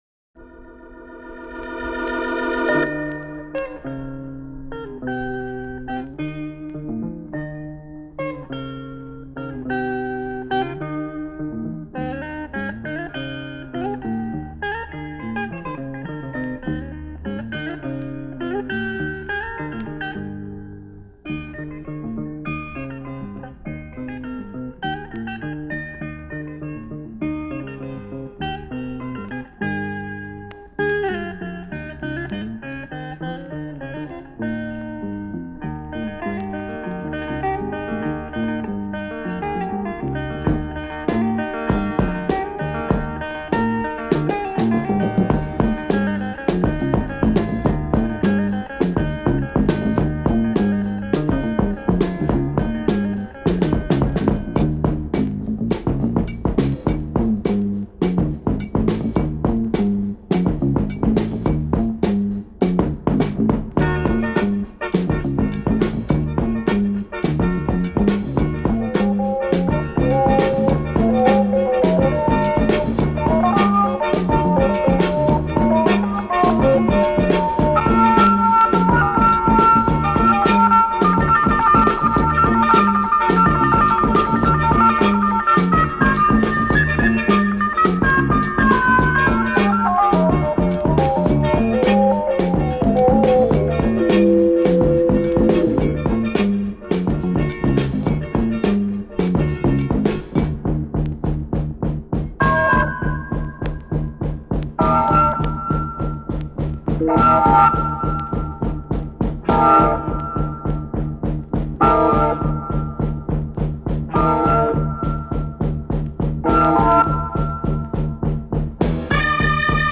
Anadolu Pop